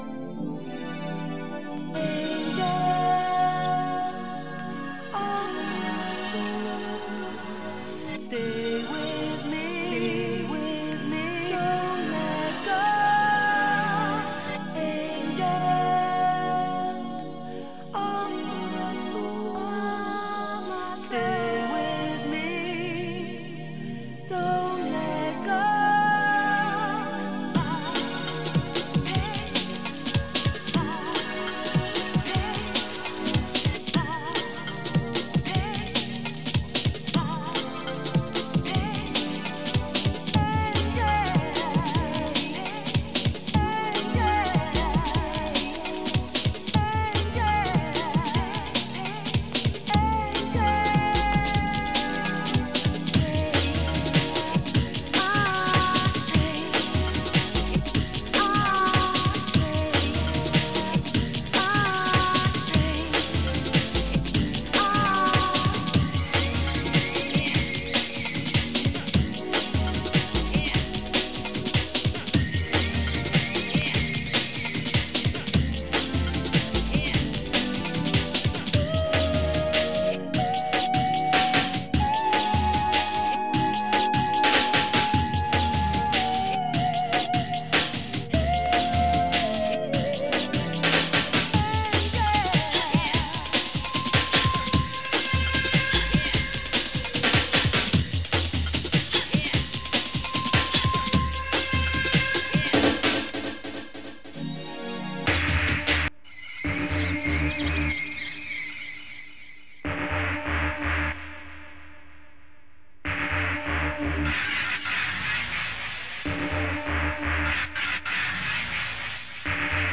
Jungle , Hardcore , Breakbeat